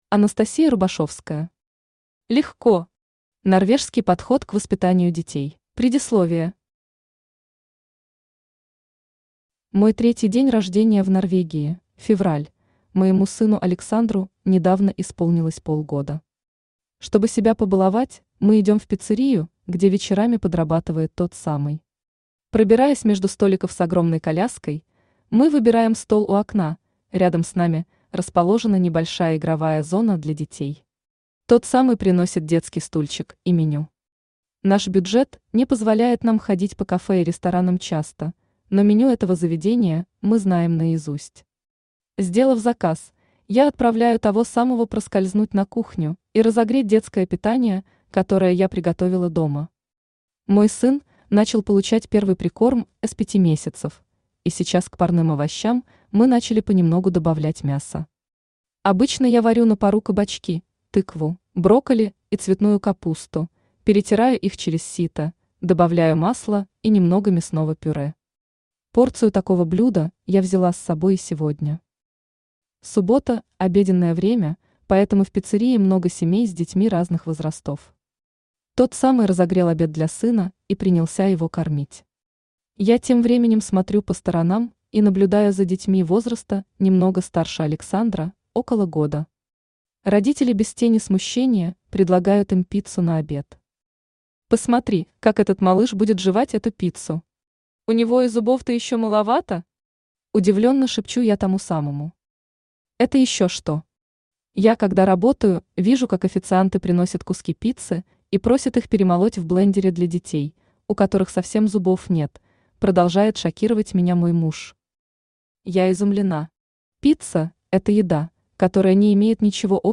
Аудиокнига Легко! Норвежский подход к воспитанию детей | Библиотека аудиокниг
Норвежский подход к воспитанию детей Автор Анастасия Рубашевская Читает аудиокнигу Авточтец ЛитРес.